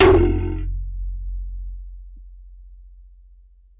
描述：来自带有2个橡皮筋和2个弹簧的接触式麦克风仪器。
Tag: 模拟 接触 橡胶带 弹簧 噪声